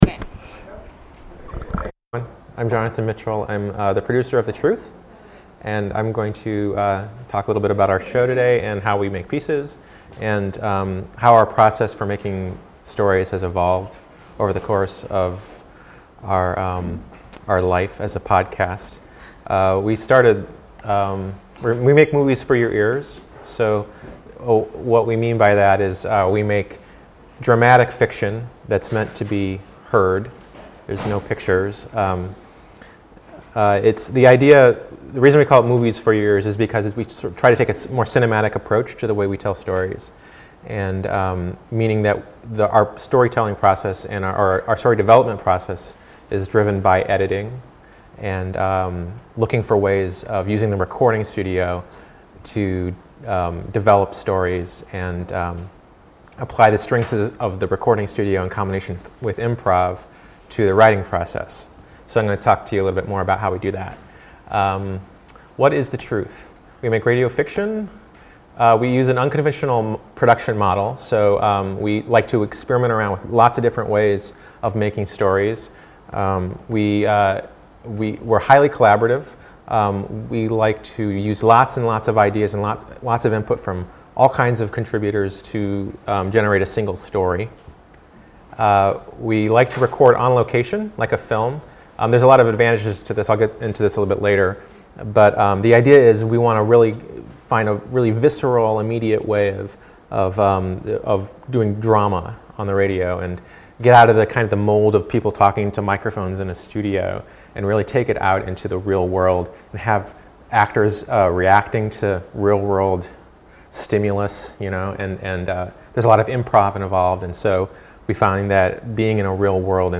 A broadcast of selected sessions from the Free Rad...